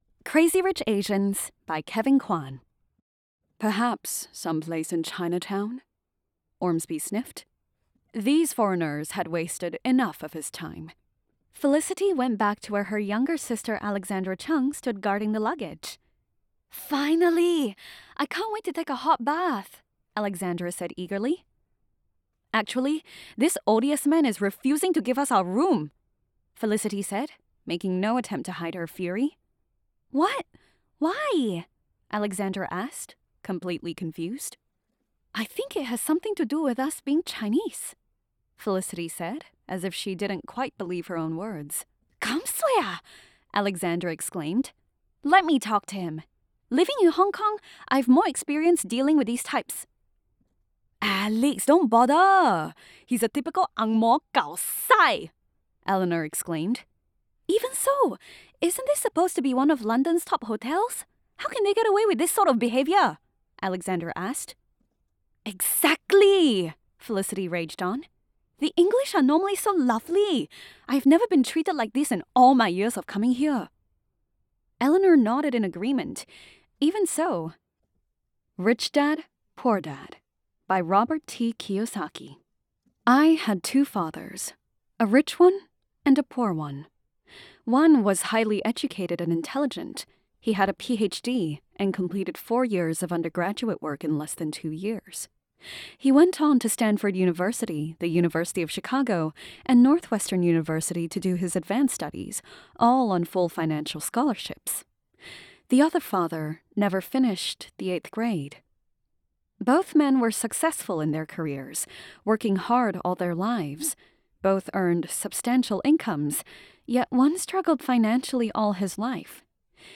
Young Adult, Adult
australian | natural
conversational
warm/friendly